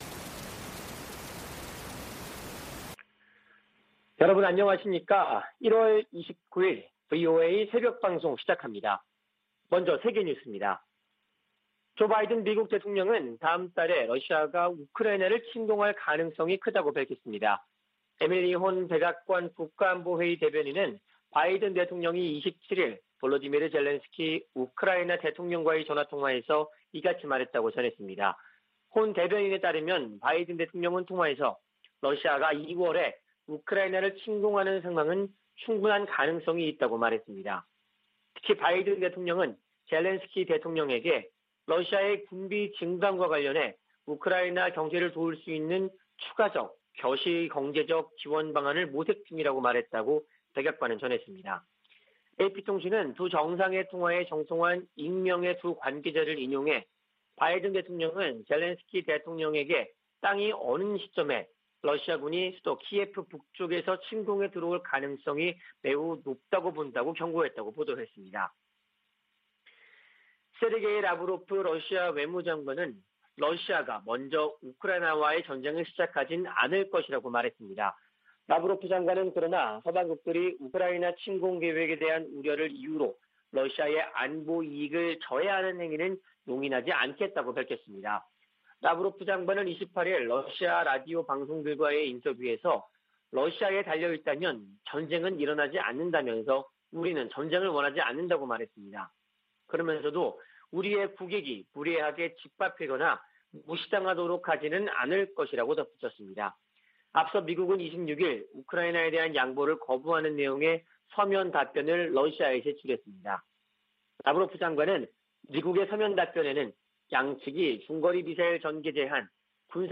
VOA 한국어 '출발 뉴스 쇼', 2021년 1월 29일 방송입니다. 북한이 27일의 지대지 전술유도탄 시험발사와 지난 25일의 장거리 순항미사일 시험발사에 각각 성공했다고 28일 공개했습니다. 미 국무부는 외교 우선 대북 접근법을 확인하면서도, 도발하면 대가를 치르게 하겠다는 의지를 분명히 했습니다. 미 국방부는 북한의 불안정한 행동을 주목하고 있다며 잇따른 미사일 발사를 ‘공격’으로 규정했습니다.